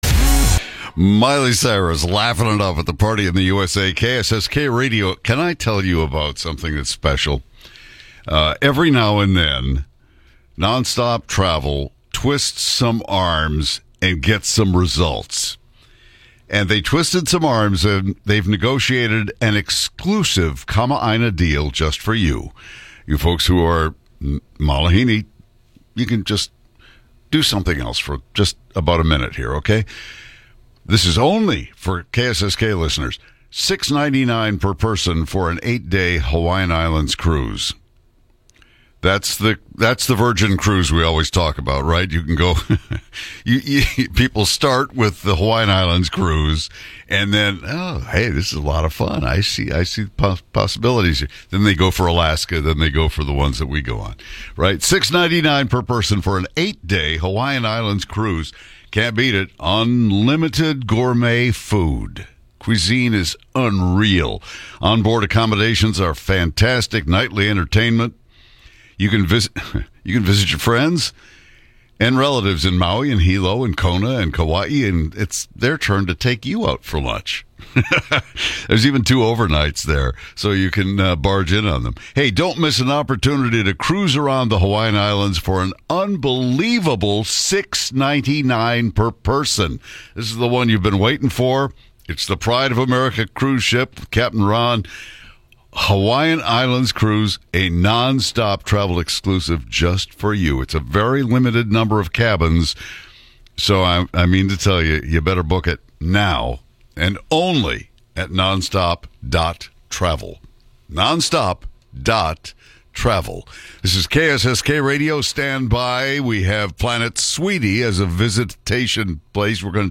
Pride of America 8-Day Cruise on the Radio with KSSK!
KSSK-FM-649AM-Non-Stop-Travel.mp3